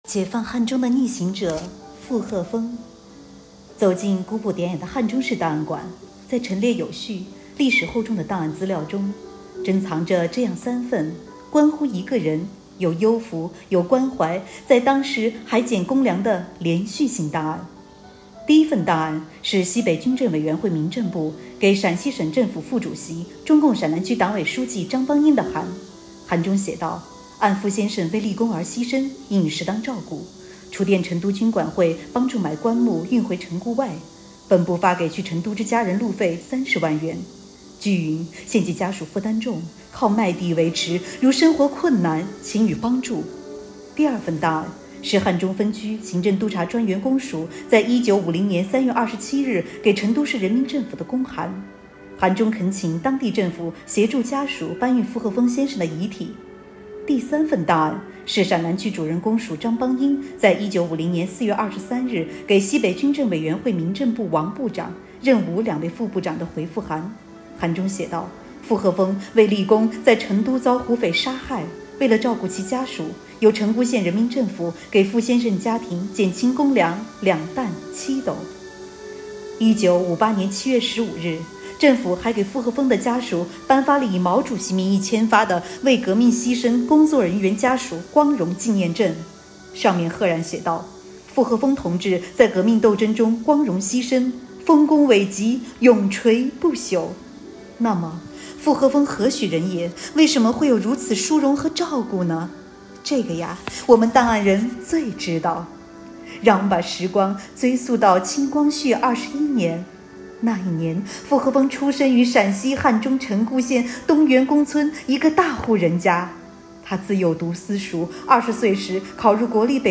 【红色档案诵读展播】解放汉中的逆行者——傅鹤峰